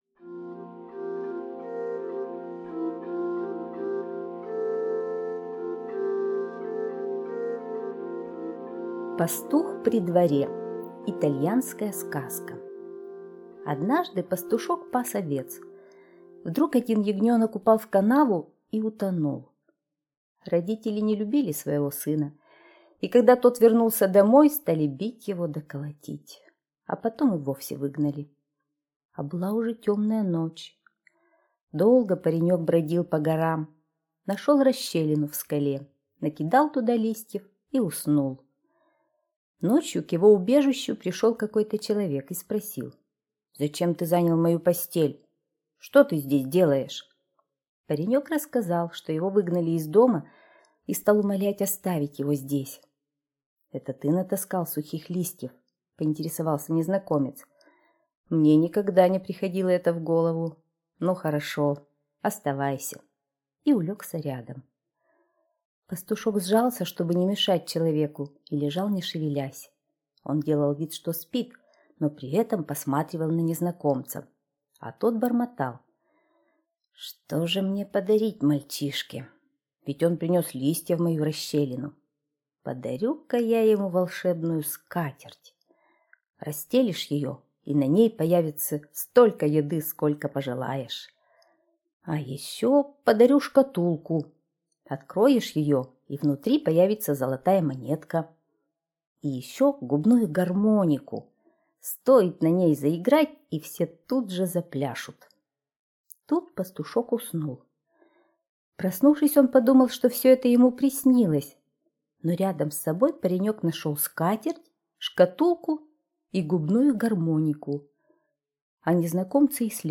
Пастух при дворе - итальянская аудиосказка - слушать онлайн